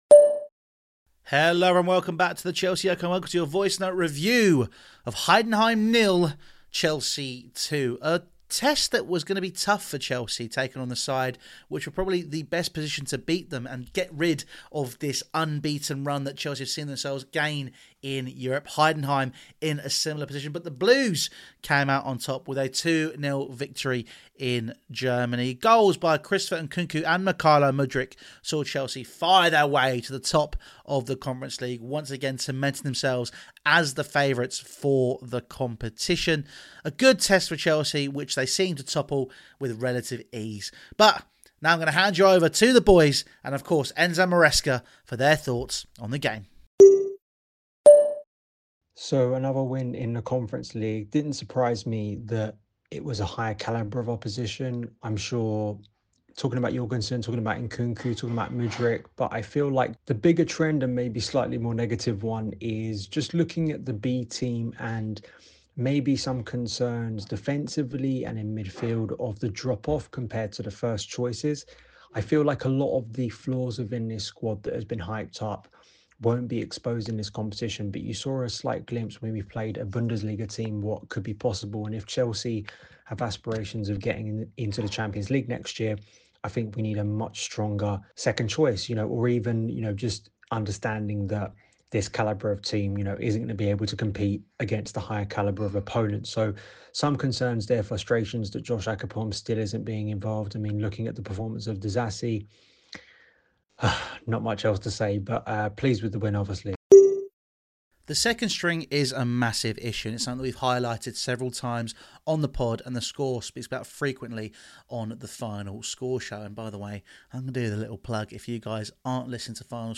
Nkunku scores AGAIN! | Heidenheim 0-2 Chelsea | Voicenote Review